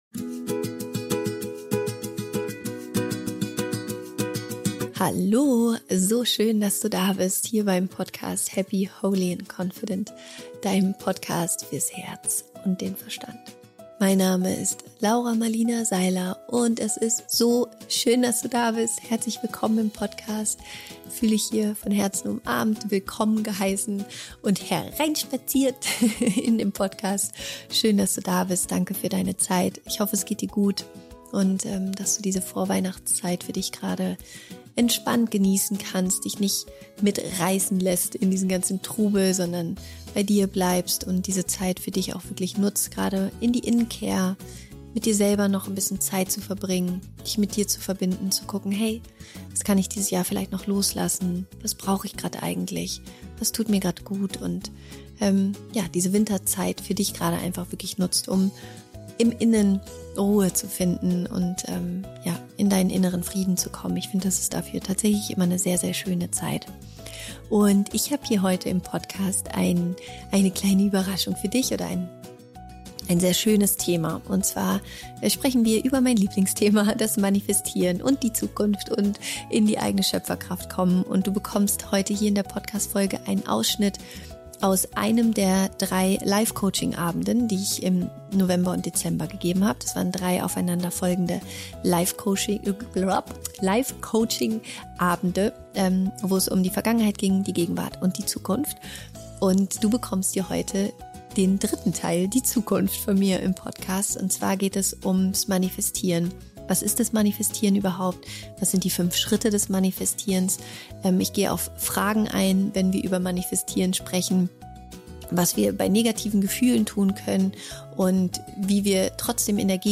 Manifestiere eine erfüllte Zukunft – Ausschnitt aus der RUSU Live Coaching Night Nr. 3
Es wartet jede Menge spannender Input rund um das Thema Manifestieren auf dich sowie eine wunderschöne Meditation, um dich mit deiner besten Zukunft zu verbinden.